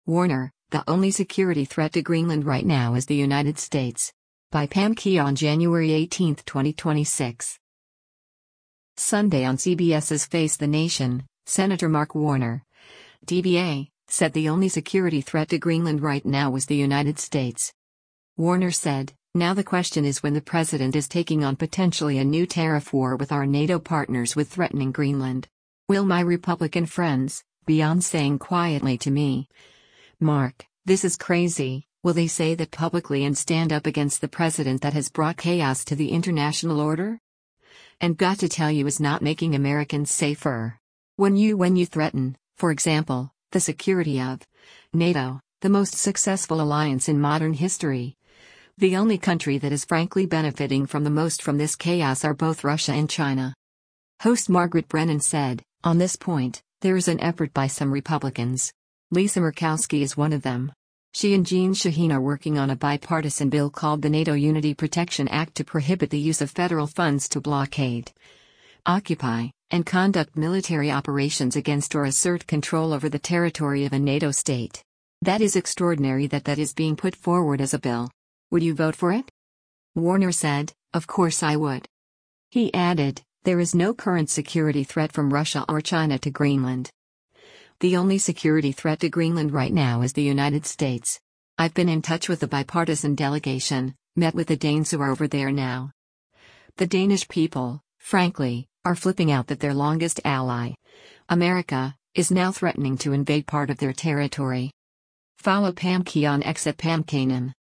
Sunday on CBS’s “Face the Nation,” Sen. Mark Warner (D-VA) said “the only security threat to Greenland right now” was the United States.